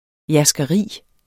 Udtale [ jasgʌˈʁiˀ ]